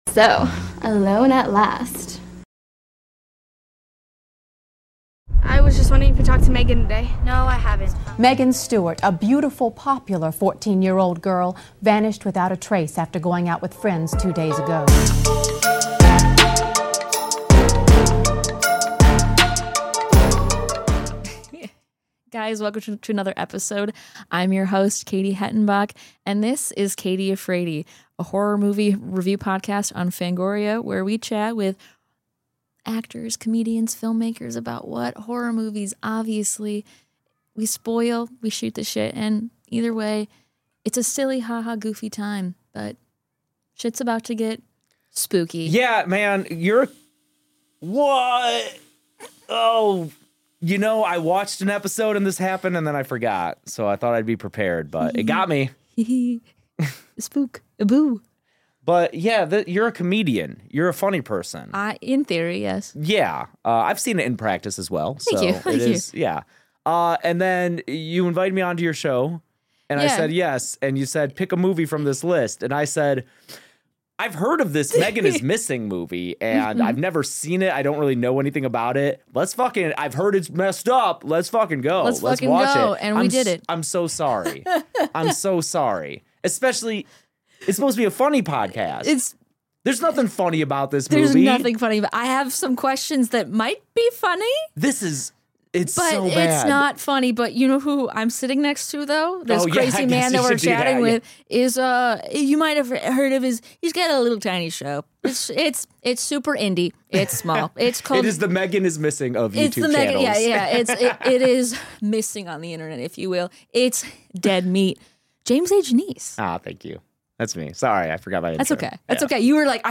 The Cave Podcast Studio